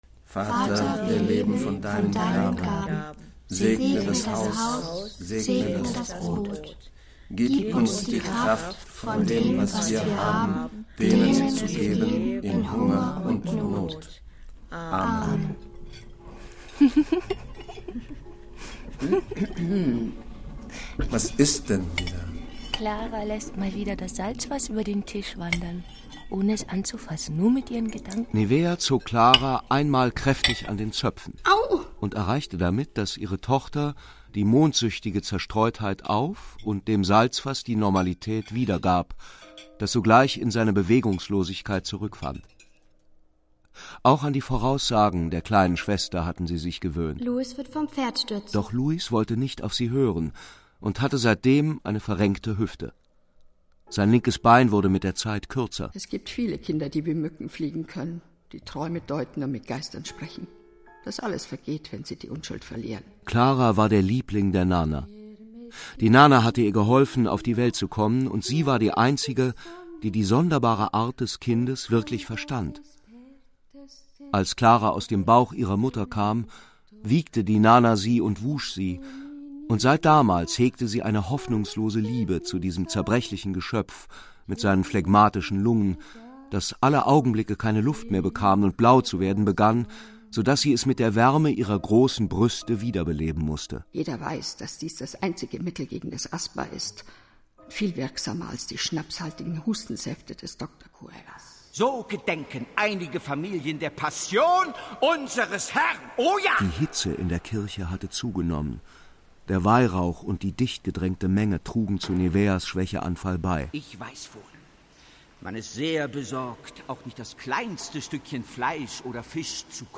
Ulrich Matthes, Angela Winkler, Manfred Zapatka, Susanne Lothar, Hans-Michael Rehberg, Sylvester Groth (Sprecher)
Ein großer Roman wird zu einer grandiosen Hörspieladaption: kein Spiel mit Geräuschen, kein Hör-Spiel im üblichen Sinne, sondern ein Hör-Rausch, großes Kino für die Ohren.